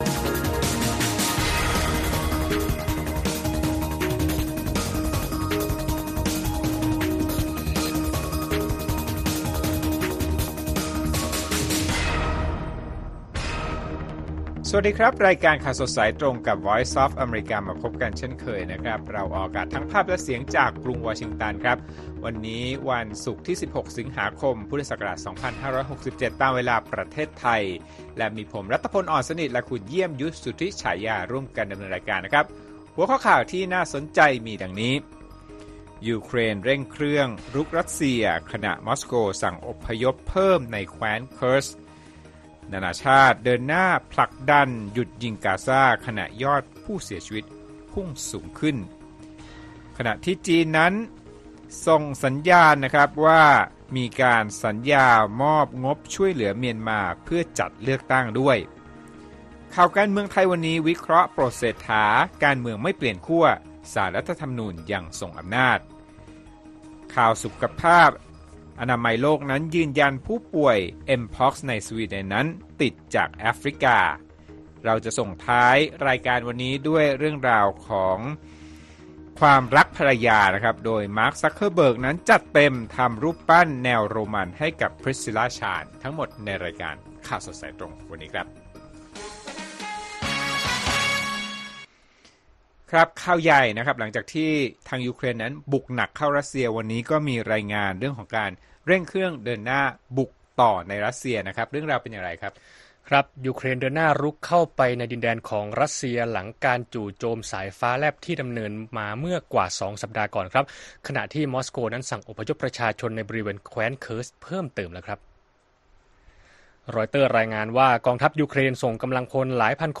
ข่าวสดสายตรงจากวีโอเอไทย วันศุกร์ ที่ 16 ส.ค. 2567